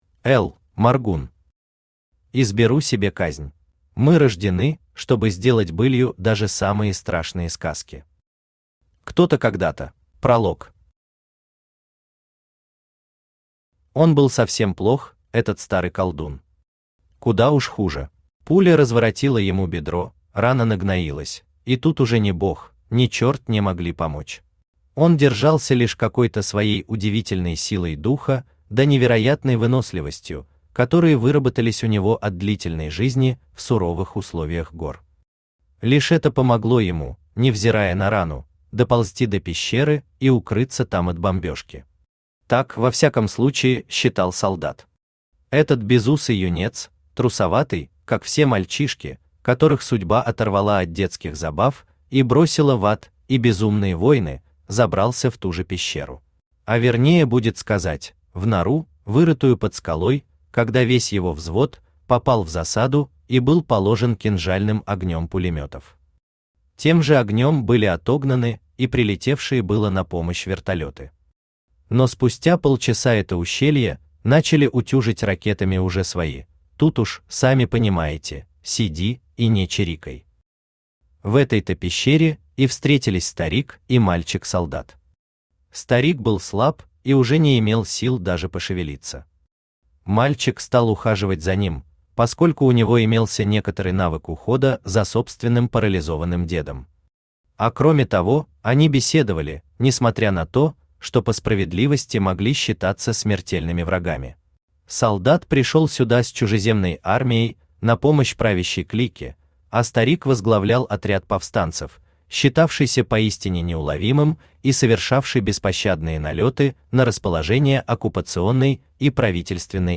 Aудиокнига Изберу себе казнь Автор Л. Моргун Читает аудиокнигу Авточтец ЛитРес.